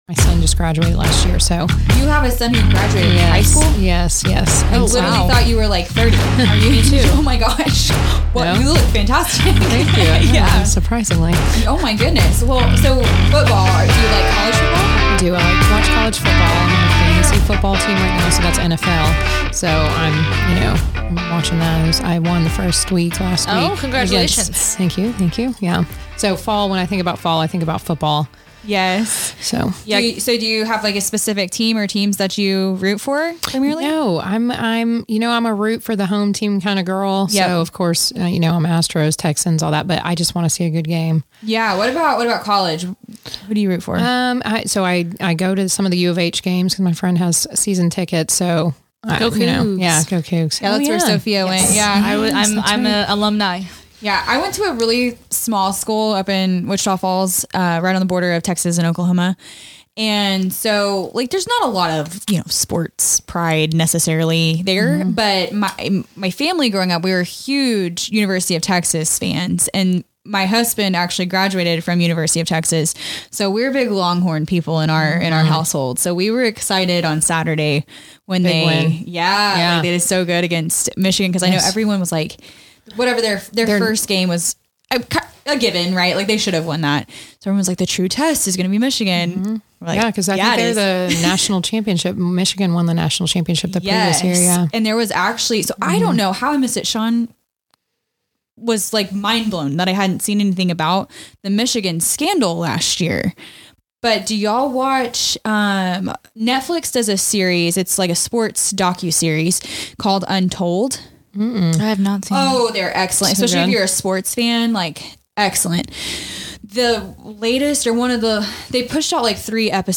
Supporting Every Student: A Conversation